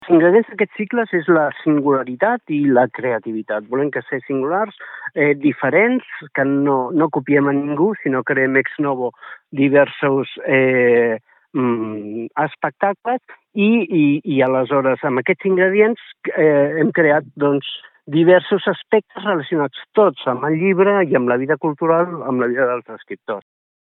Per saber com va el projecte i què en podem esperar, n’hem parlat amb el regidor de Cultura de Calonge, Norbert Botella.